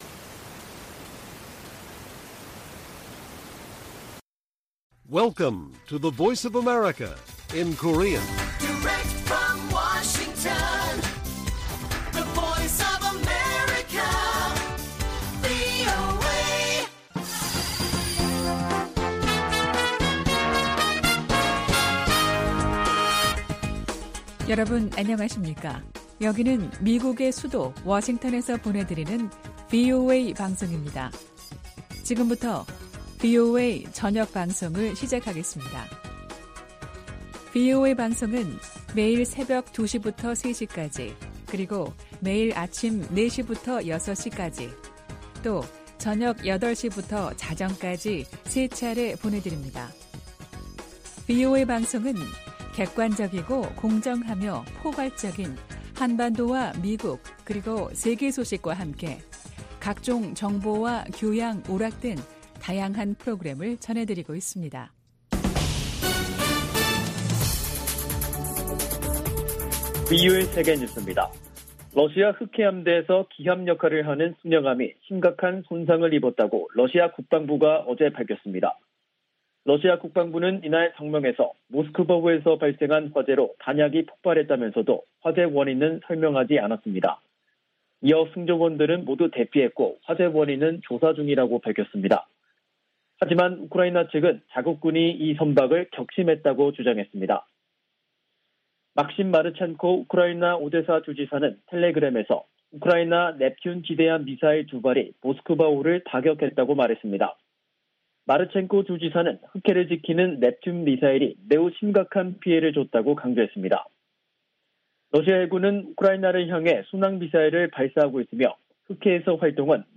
VOA 한국어 간판 뉴스 프로그램 '뉴스 투데이', 2022년 4월 14일 1부 방송입니다. 미 7함대는 에이브러햄 링컨 항모가 동해에서 일본 자위대와 연합훈련을 실시하고 있다며 북한에 적대적인 의도는 없다고 밝혔습니다. 미 상·하원이 중국 견제 법안에 관한 조율 절차에 들어갔습니다.